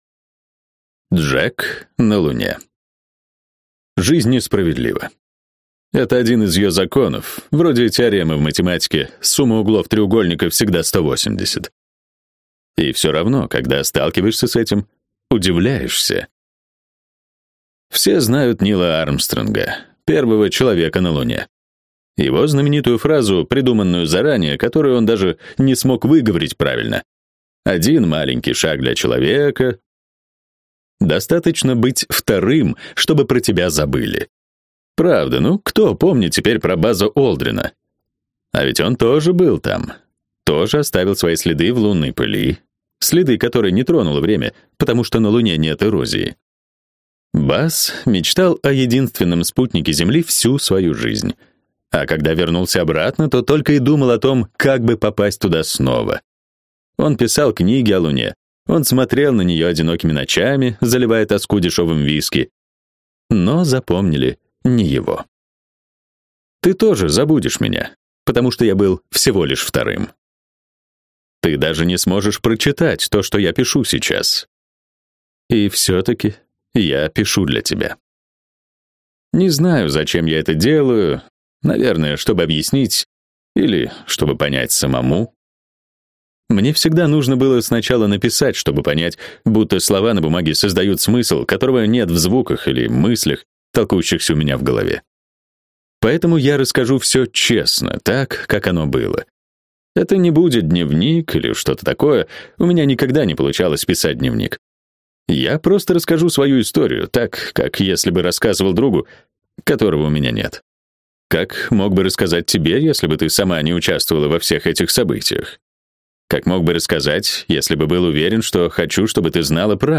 Аудиокнига Джек на Луне | Библиотека аудиокниг
Прослушать и бесплатно скачать фрагмент аудиокниги